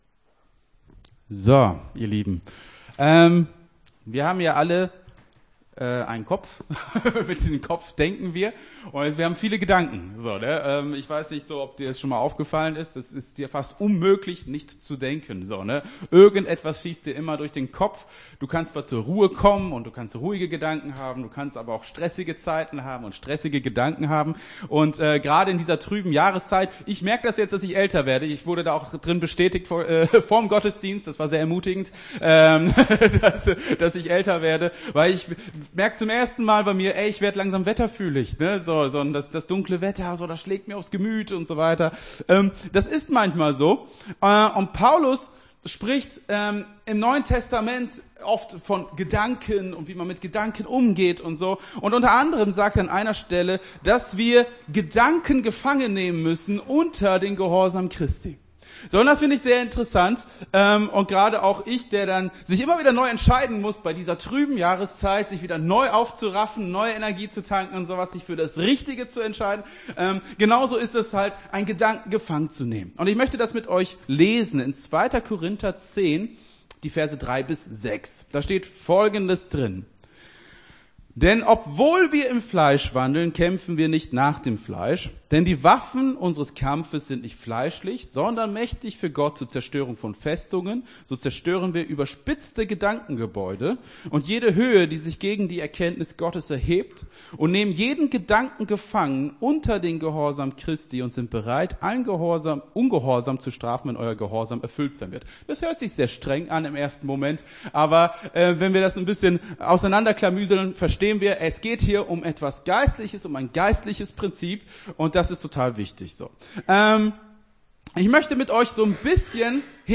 Gottesdienst 23.01.22 - FCG Hagen